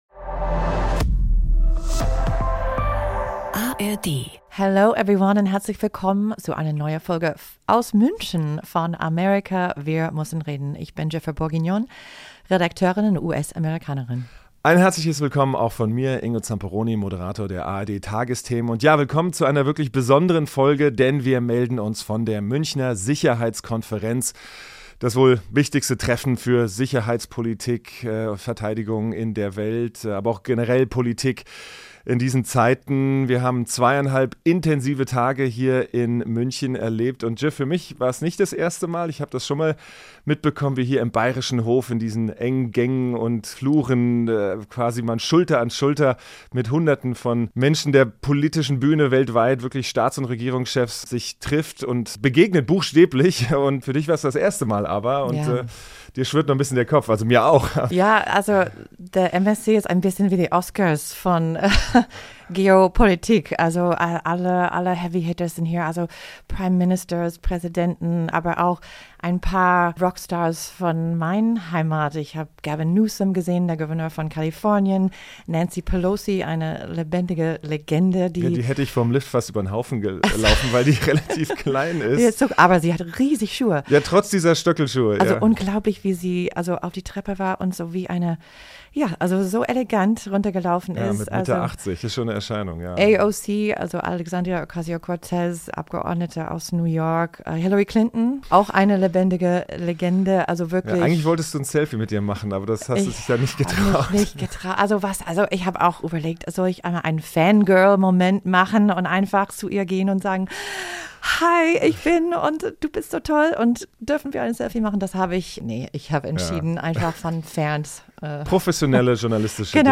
Die Vierer-Runde diskutiert die globalen Folgen der neuen Weltordnung und was von München übrigbleibt.